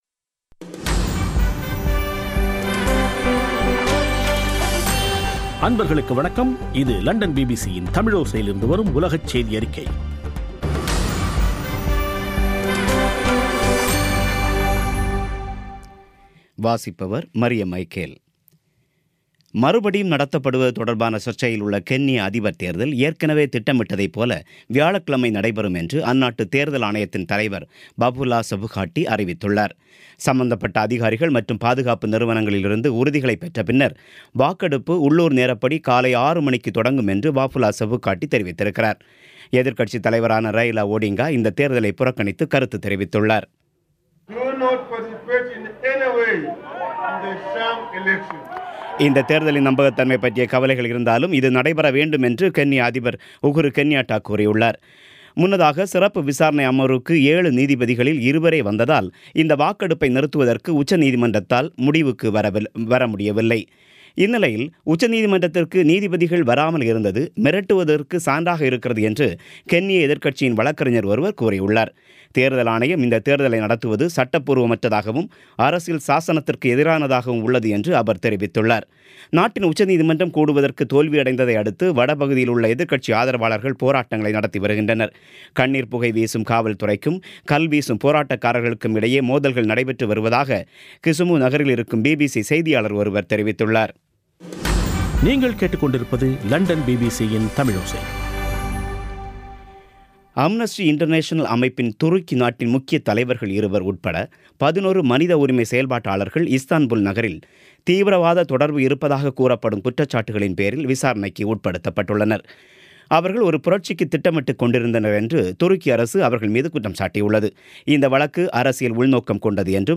பிபிசி தமிழோசை செய்தியறிக்கை (25/10/2017)